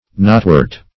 Search Result for " knotwort" : The Collaborative International Dictionary of English v.0.48: Knotwort \Knot"wort`\ (n[o^]t"w[^u]rt`), n. (Bot.) A small, herbaceous, trailing plant, of the genus Illecebrum ( Illecebrum verticillatum ).
knotwort.mp3